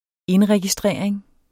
Udtale [ ˈenʁεgiˌsdʁεˀɐ̯eŋ ]